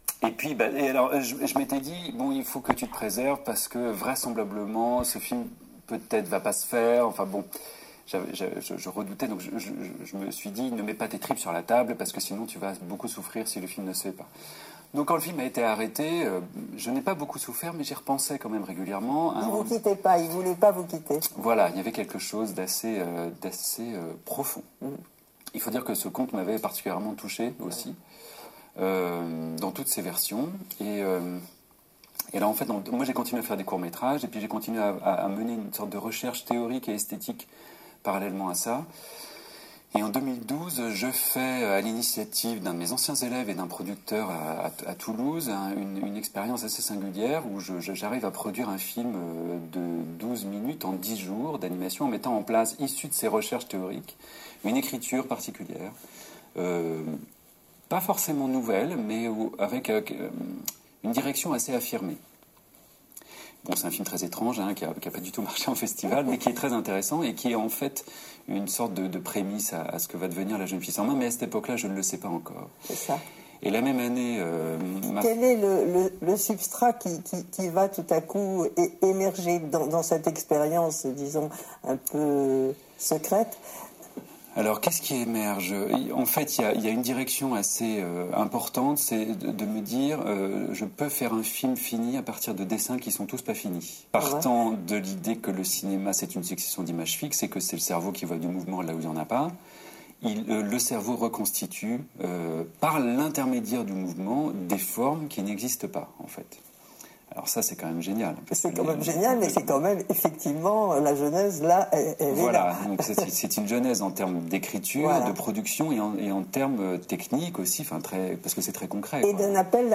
La jeune fille sans nom – interview – extraits
la-jeune-fille-sans-nom-interview-extraits.mp3